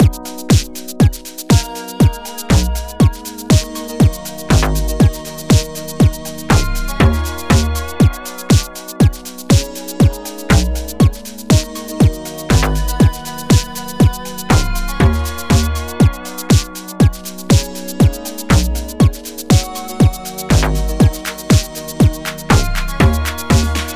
Music - Song Key
Gb Major